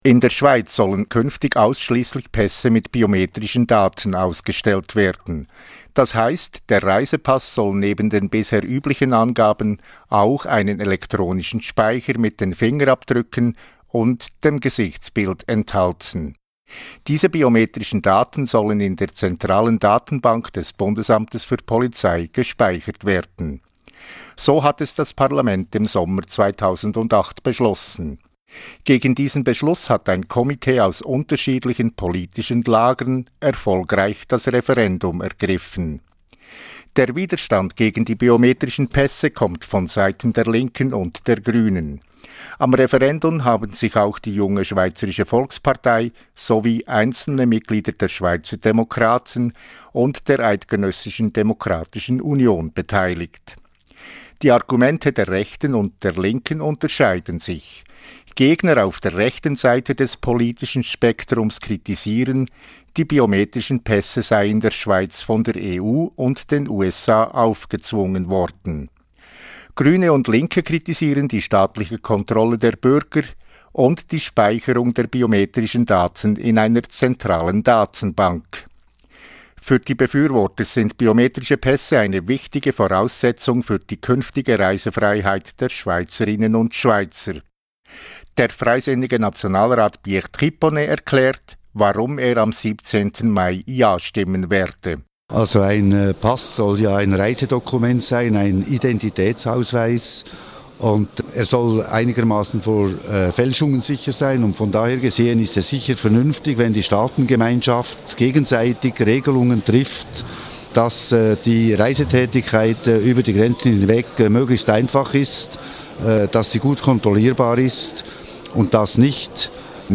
Gegner und Befürworter der biometrischen Pässe kommen hier zu Wort.